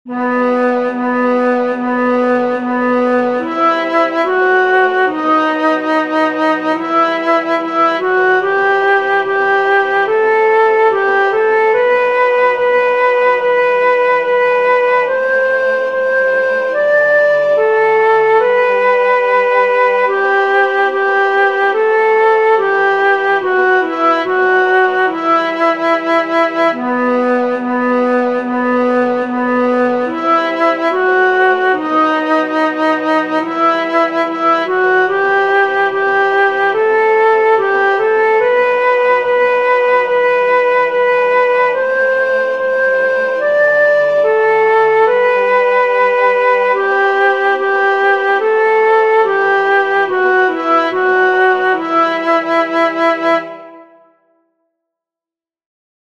El tempo indicado es Calmo, negra= 72.